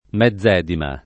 mezzedima [ m Hzz$ dima ]